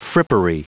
Prononciation du mot frippery en anglais (fichier audio)
Prononciation du mot : frippery